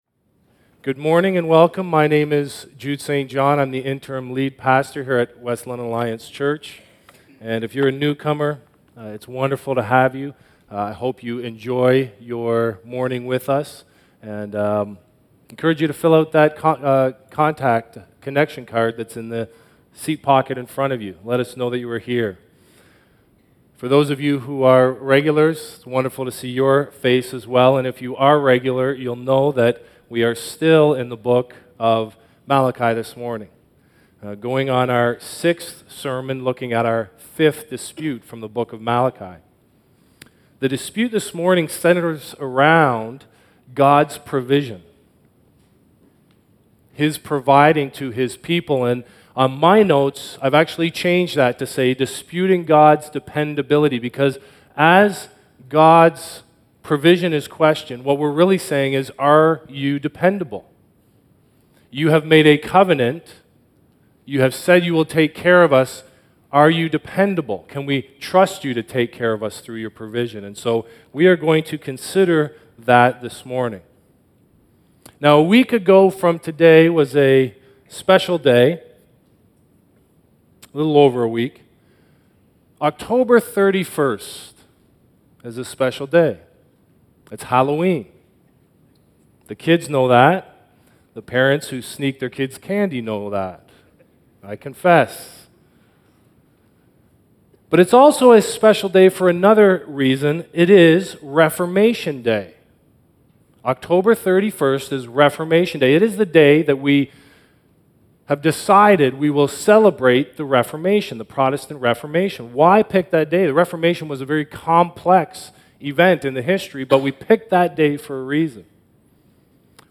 Sermon Archives